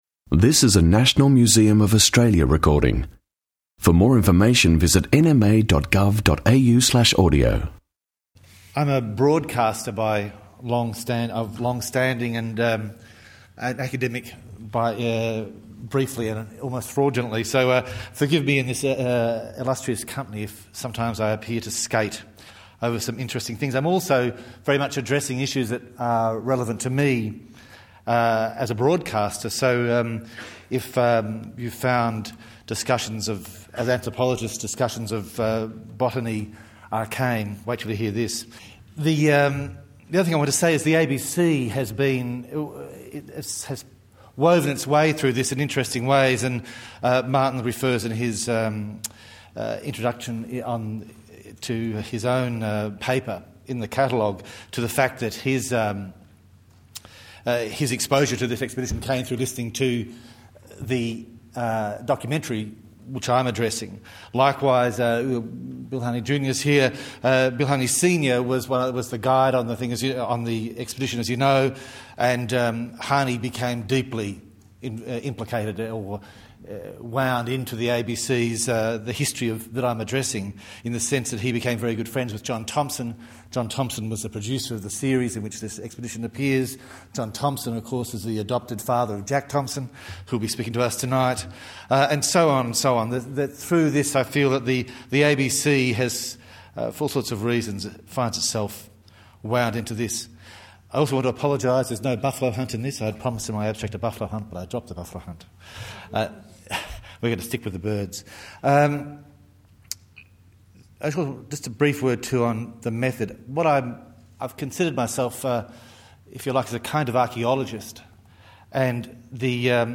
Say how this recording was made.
Barks, Birds and Billabongs symposium 18 Nov 2009